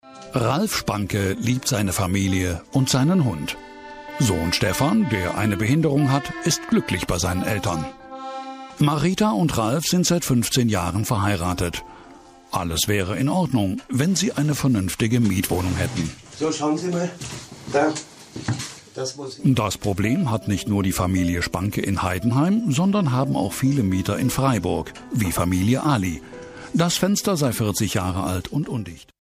TV-Off, Shows, Reality-Doku, voice-over…
TV-Off: “Die Akte GAGFAH” (Ausschnitte)